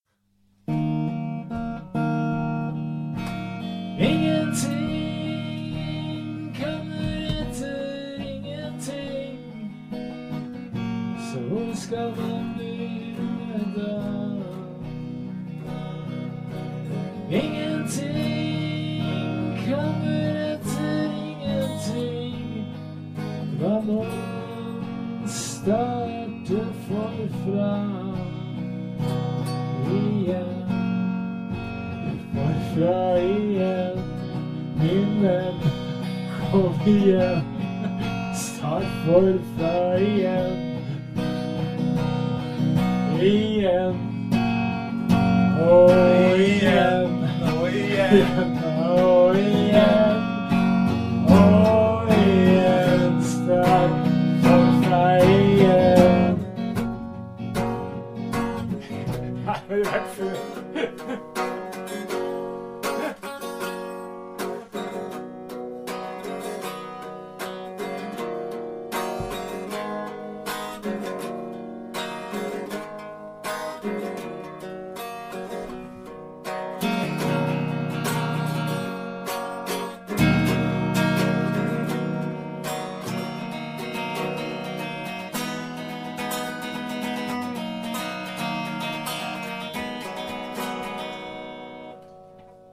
Ganska tidigt in på det nya året hade vi en tre timmars lång jam, där vi var väldigt inspirerade, och skapade mycket nytt.